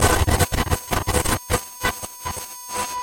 Звуки помех, глитча
ТВ сигнал плохо показывает глитчит